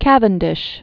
(kăvən-dĭsh), Henry 1731-1810.